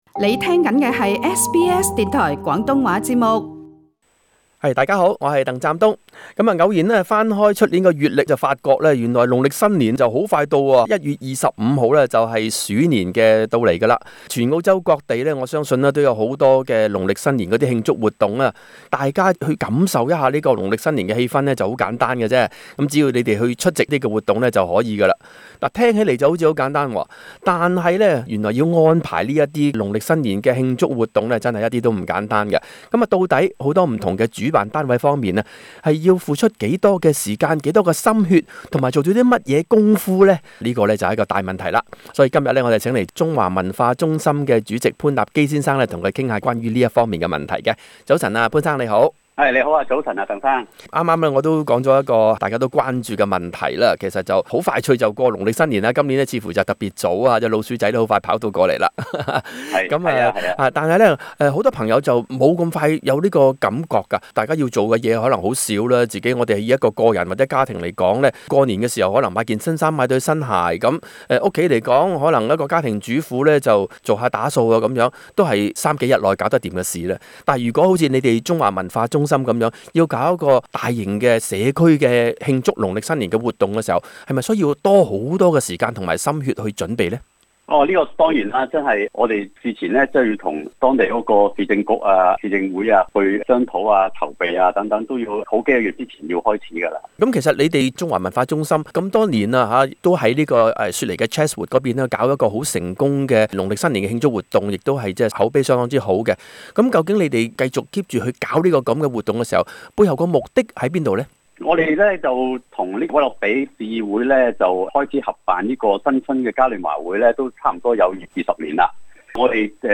【社區專訪】如何去籌備農曆新年的慶祝活動?